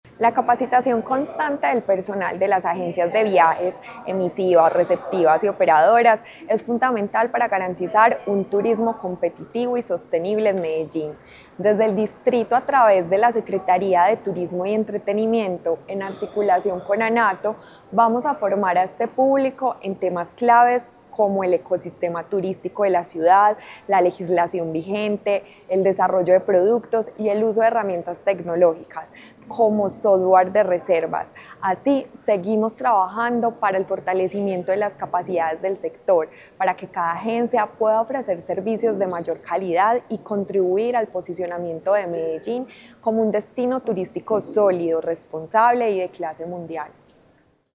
De las agencias beneficiadas, 20 continuarán con la metodología del Plan Declaraciones de la secretaria (e) de Turismo y Entretenimiento, Ana María Mejía.
Declaraciones-de-la-secretaria-e-de-Turismo-y-Entretenimiento-Ana-Maria-Mejia..mp3